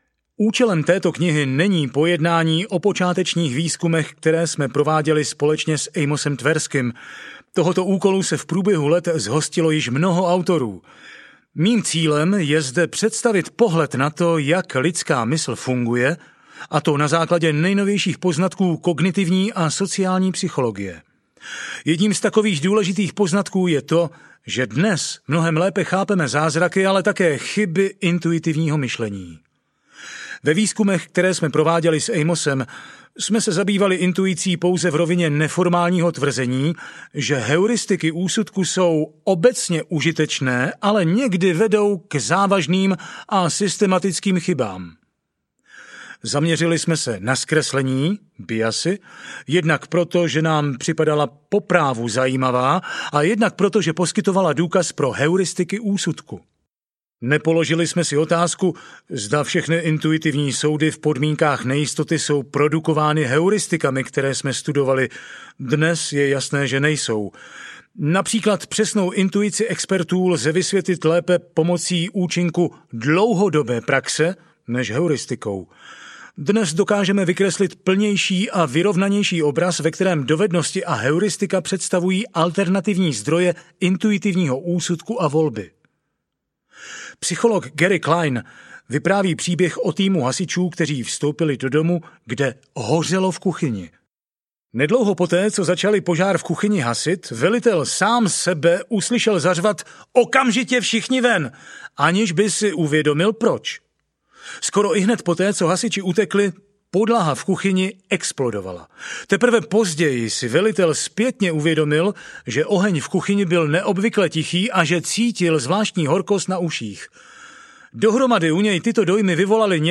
Myšlení, rychlé a pomalé audiokniha
Ukázka z knihy